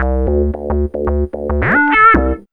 2808L B-LOOP.wav